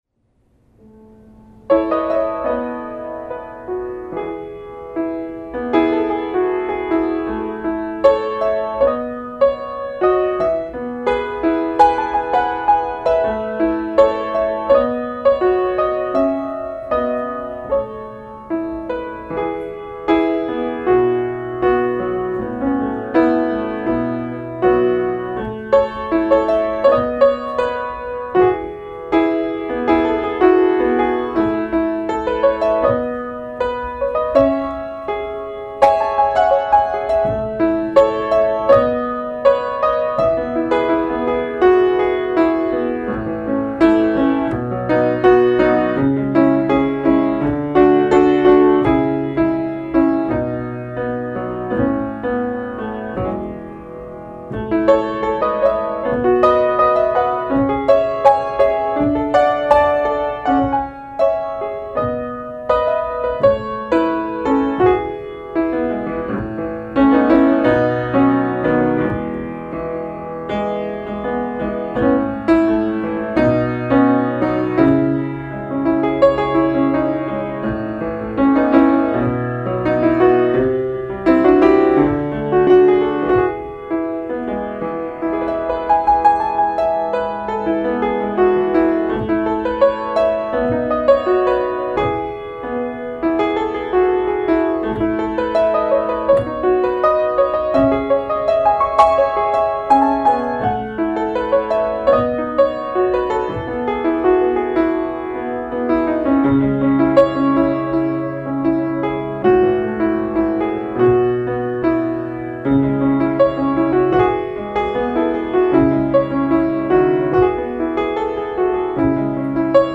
Index of /music/pianoSketches